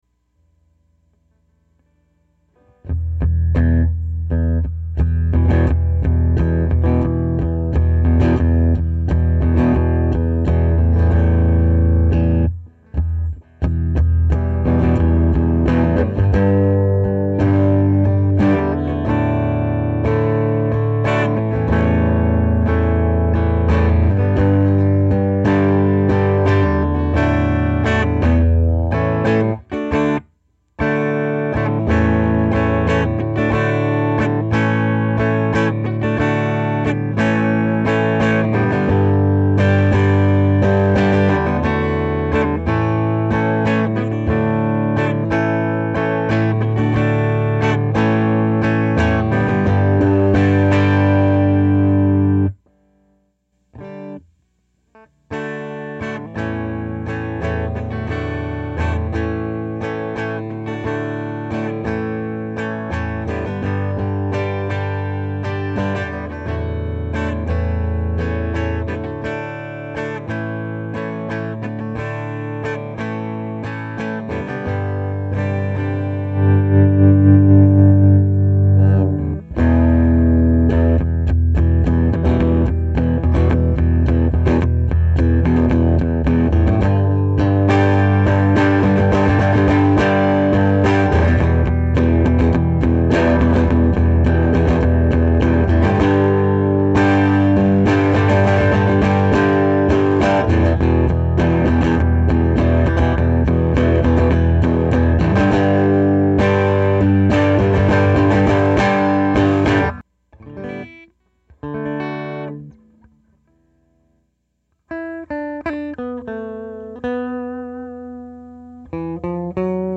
It's a little muddier than what I'm hearing real-time. I still haven't mastered recording.
My tele sounds like a, well, tele.
Tele>Deluxe>SM57>E-mu0404>Audigy 2 ZS>Reaper
Guitar's plugged into #1 Normal channel (#2 is lower gain -- there are also two inputs for the 'Bright' channel).
Tone is on 7, volume was either on 4 or 12 for the normal channel and always on 2 on the bright channel.
I also varied the volume on my guitar and the tone pot was rolled down about 1/3 of the way.
The sounds are all tweed -- from spanky cleans to full-on raunch (see clip above).